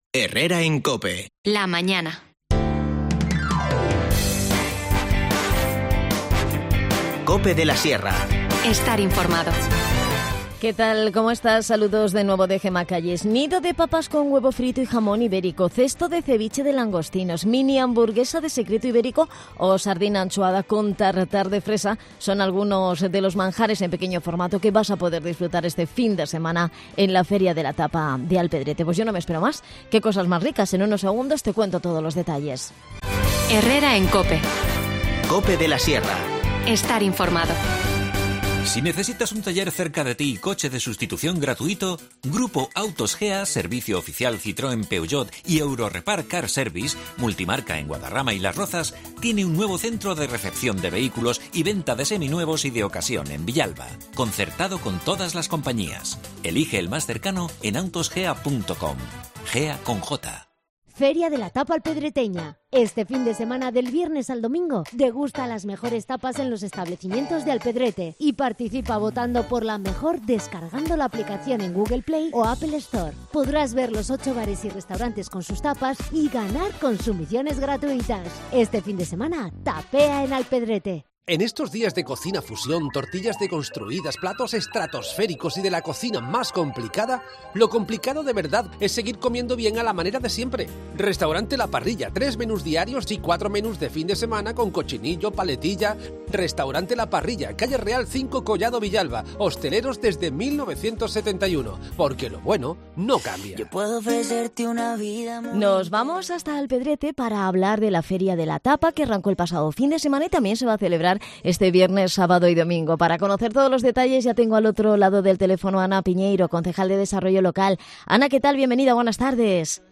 Ana Piñeiro, concejal de Desarrollo Local, nos adelanta todos los detalles y nos cuenta cómo van las obras del futuro Museo del Cantero.